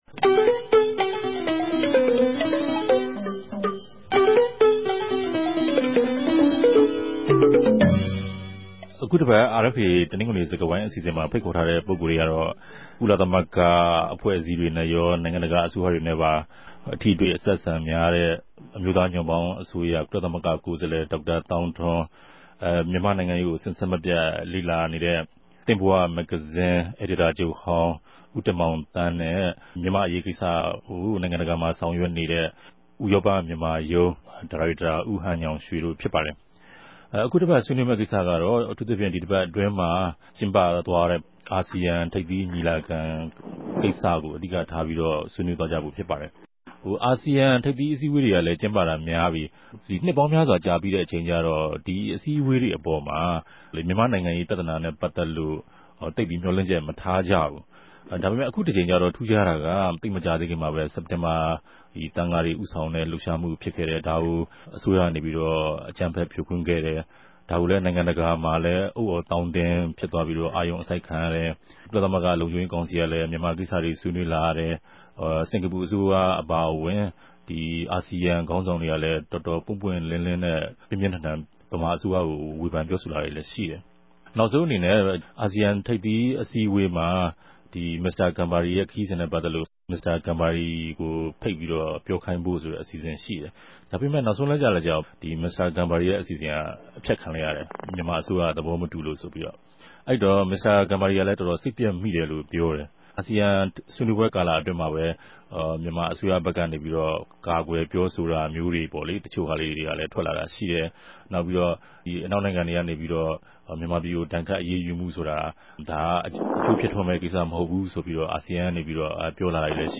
တယ်လီဖုန်းနဲႚ ဆက်သြယ်္ဘပီး ဆြေးေိံြးတင်ူပထားပၝတယ်။